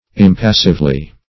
impassively - definition of impassively - synonyms, pronunciation, spelling from Free Dictionary
-- Im*pas"sive*ly, adv.